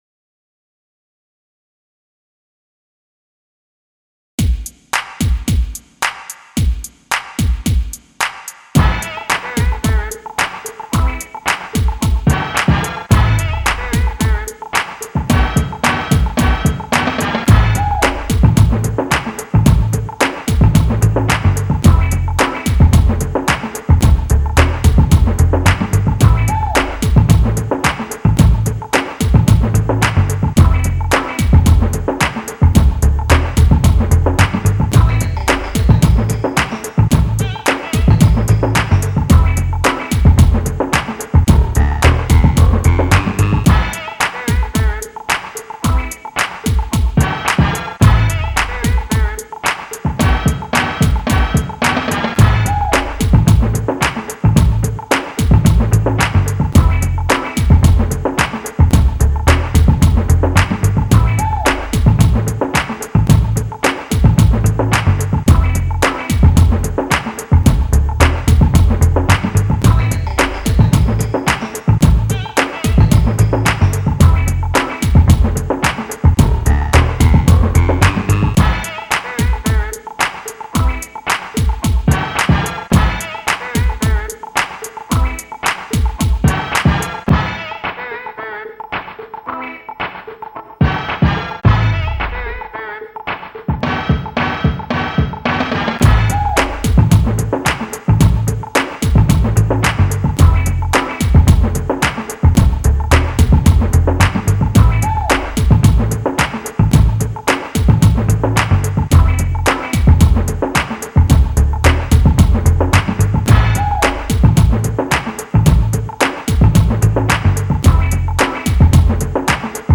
inst
remix